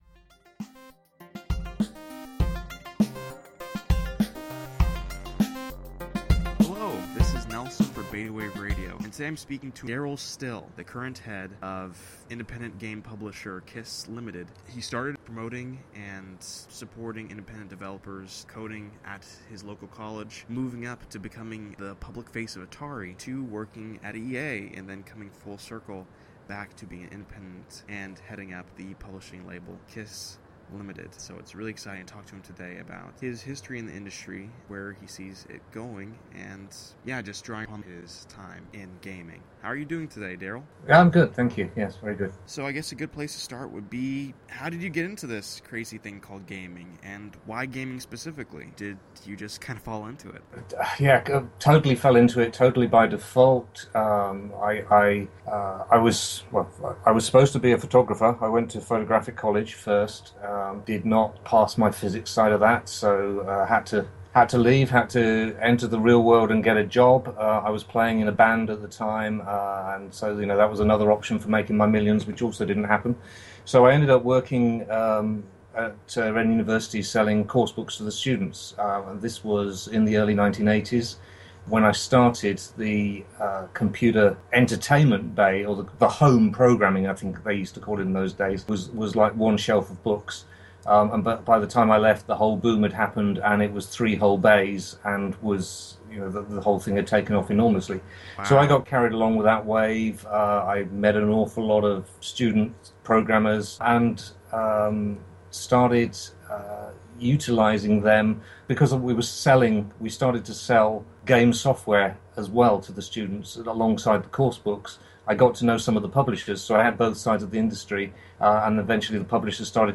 *The interview below was recorded May 28th, 2015*